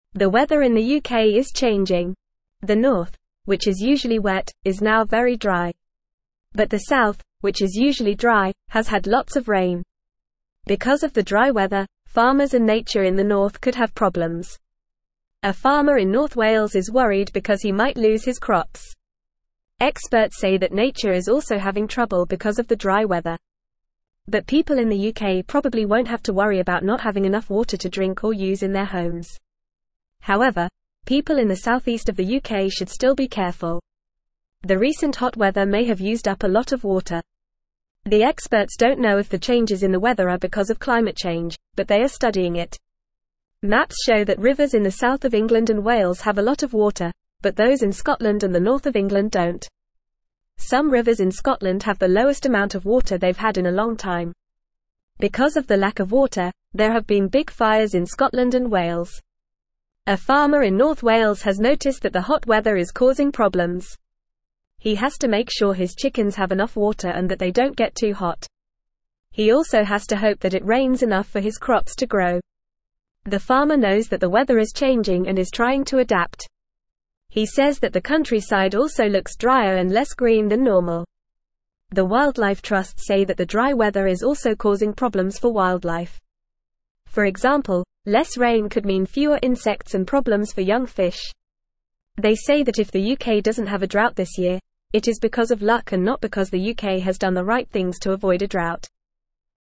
Fast
English-Newsroom-Beginner-FAST-Reading-UK-Weather-Changes-Worry-Farmers-and-Nature.mp3